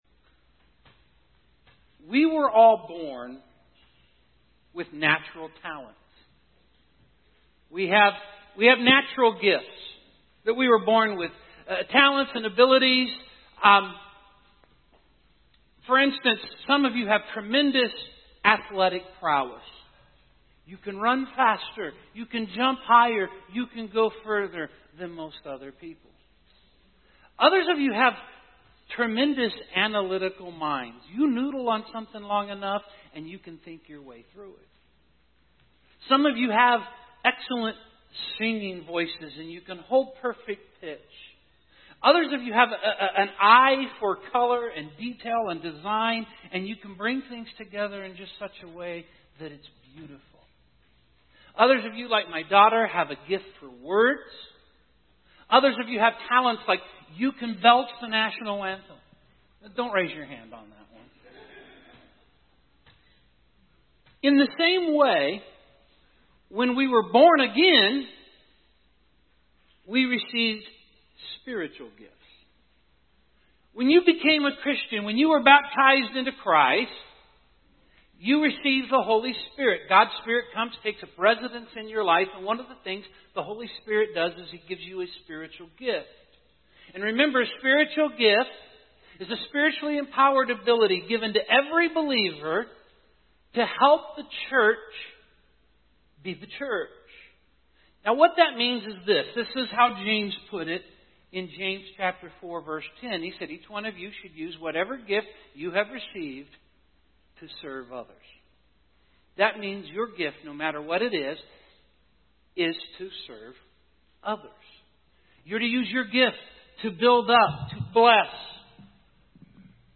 In this sermon we will learn four helpful hints that can guide us in discovering our spiritual gift.